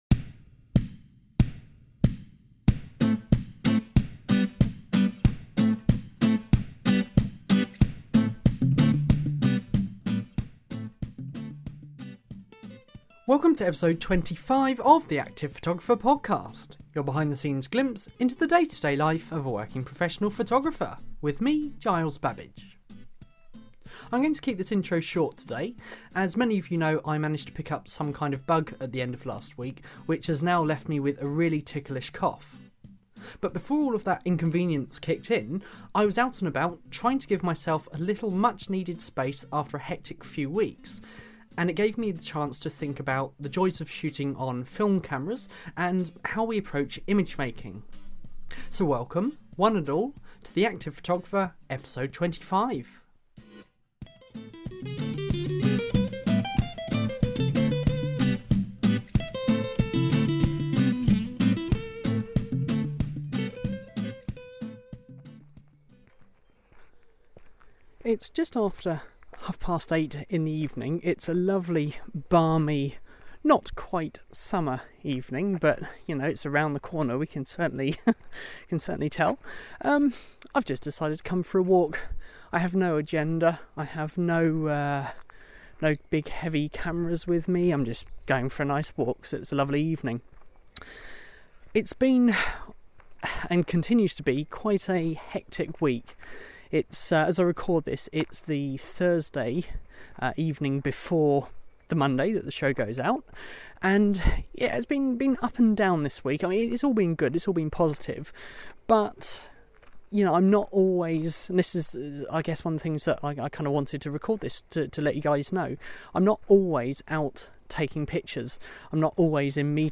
This week: I’m strolling along a local ancient footpath, taking a little time out from everything.